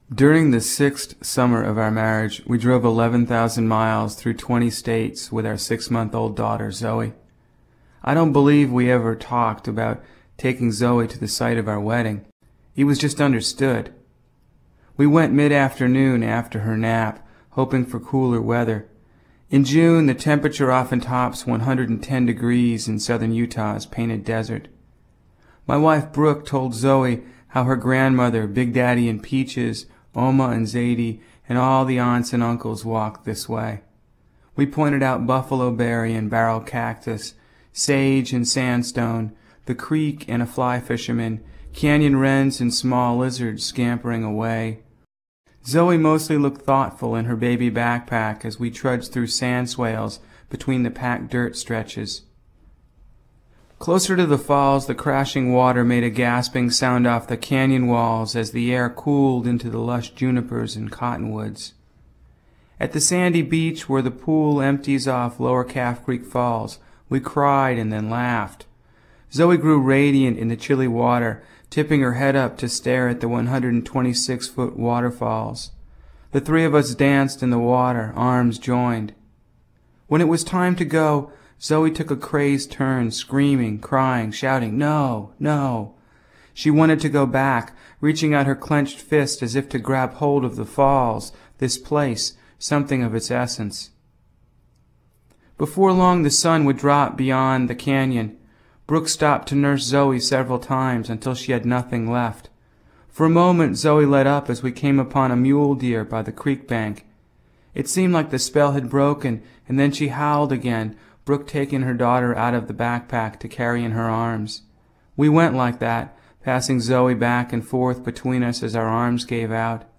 essays for Utah Public Radio